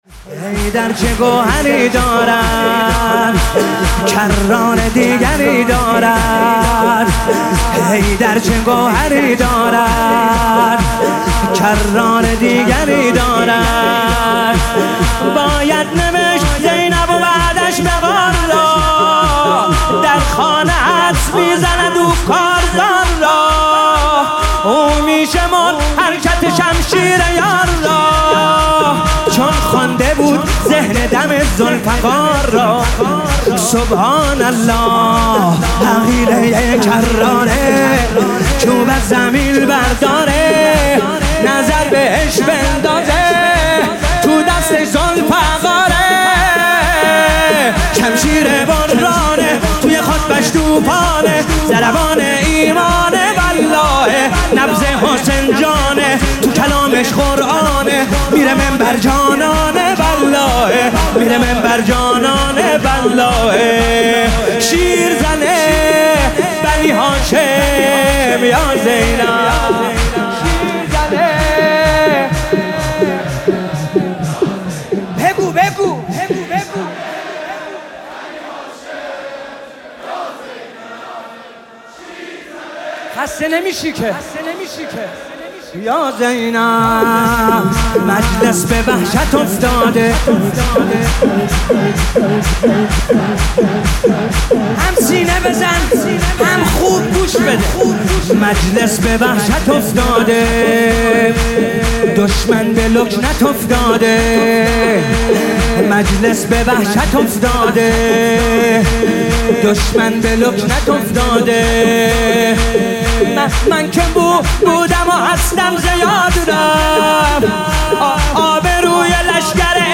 گلچین فایل صوتی سخنرانی و مداحی شب چهارم محرم، اینجا قابل دریافت است.
حسین طاهری - شور